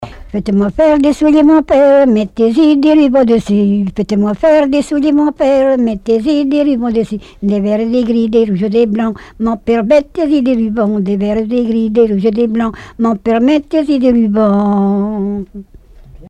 branle
Divertissements d'adultes - Couplets à danser
collecte en Vendée
Répertoire de chants brefs et traditionnels